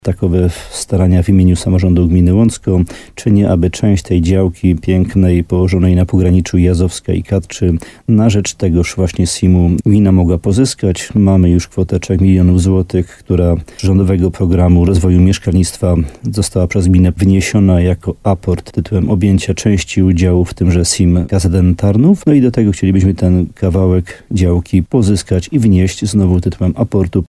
Wójt Jan Dziedzina poinformował w programie Słowo za słowo na antenie RDN Nowy Sącz, że jest już po wstępnych rozmowach z wojewodą w tej sprawie.